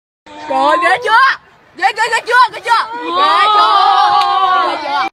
Tải âm thanh "Ghê chưa ghê chưa" - Hiệu ứng âm thanh chỉnh sửa video